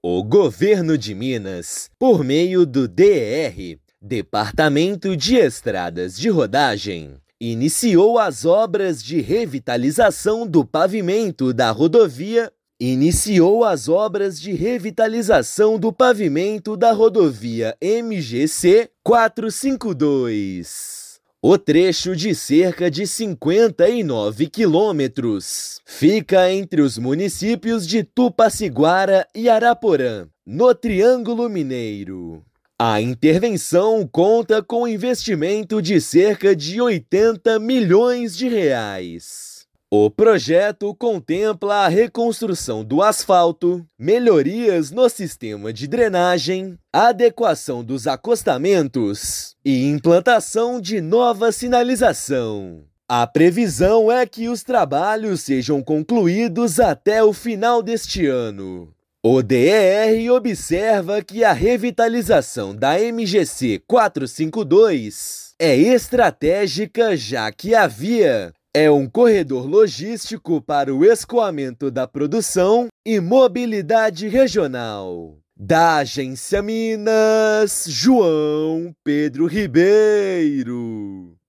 Sistema de 'pare e siga' será adotado no trecho entre Tupaciguara e Araporã; motoristas devem redobrar a atenção durante as obras. Ouça matéria de rádio.